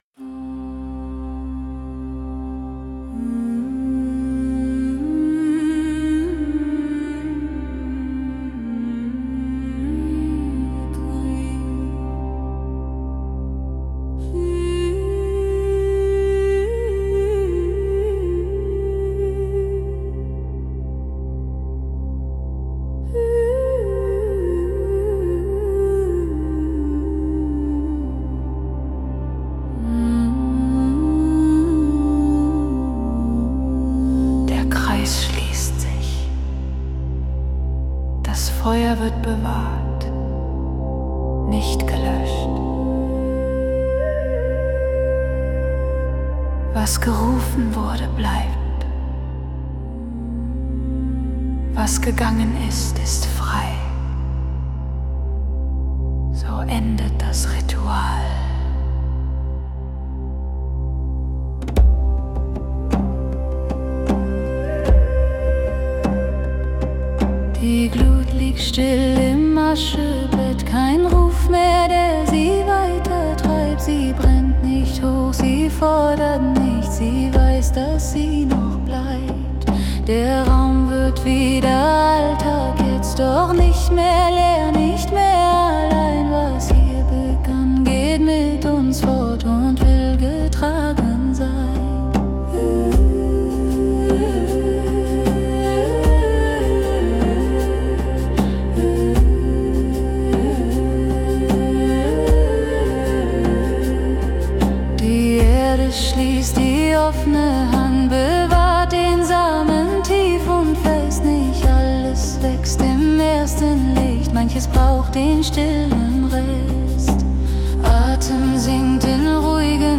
Ein ruhiger, würdevoller Ausklang, der Nachwirkung erlaubt.